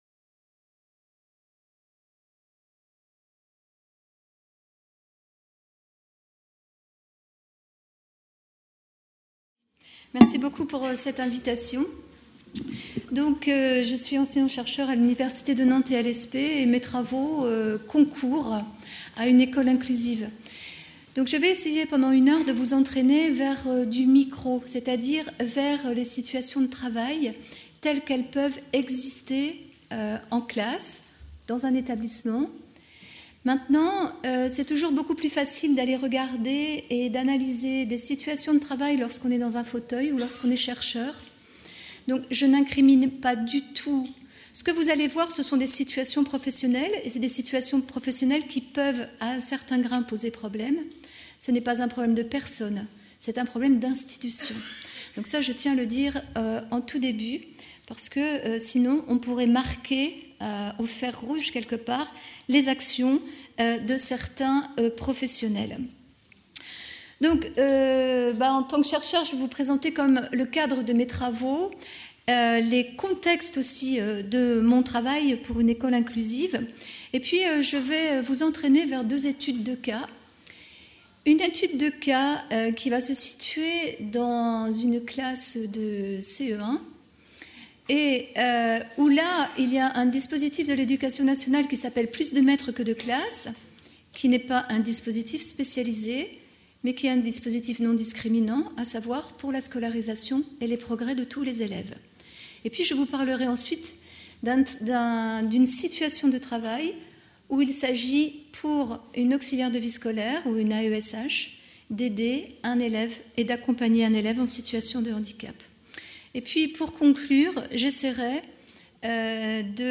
Colloque international Education inclusive : coopérer pour scolariser La coopération au service de l’inclusion : à quelles conditions ?